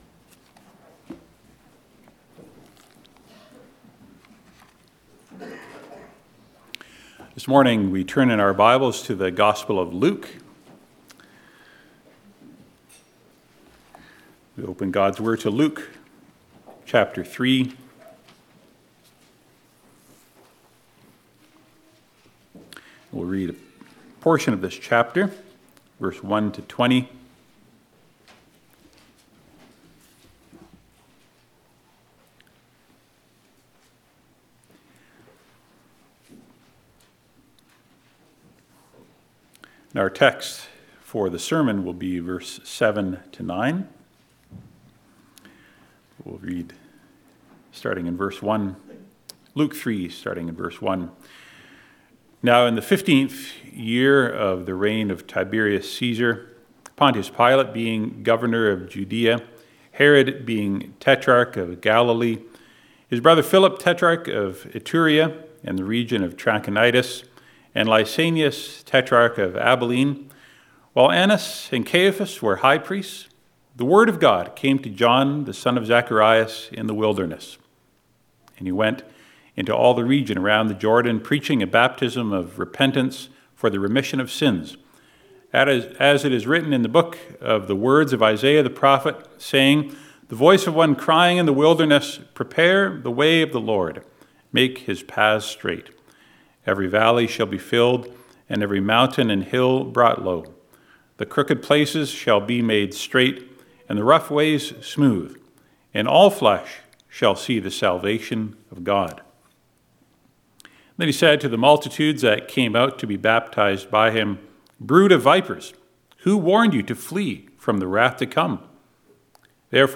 Passage: Luke 3:1-20 Service Type: Sunday Morning « Jesus said